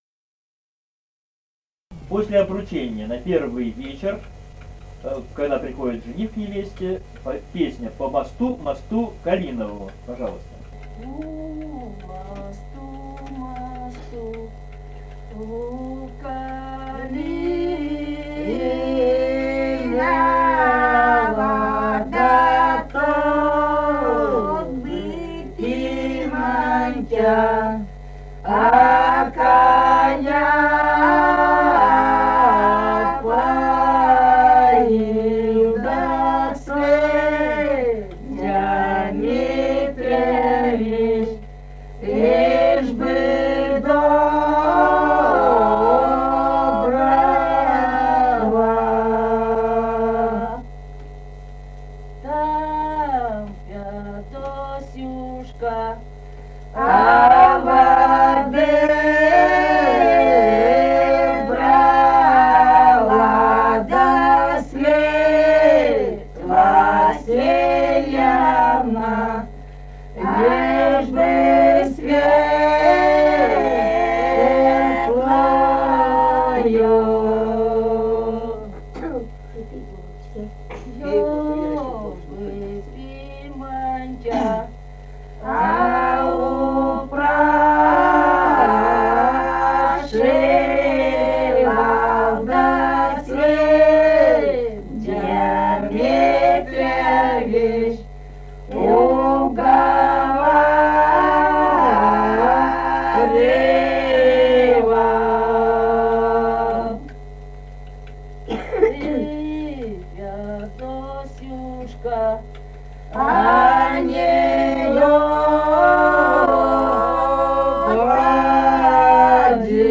Русские песни Алтайского Беловодья 2 [[Описание файла::«По мосту, мосту калинову», свадебная; поют в первый вечер после обручения, когда жених приходит к невесте в гости.
Республика Казахстан, Восточно-Казахстанская обл., Катон-Карагайский р-н, с. Фыкалка, июль 1978.